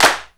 INSTCLAP13-R.wav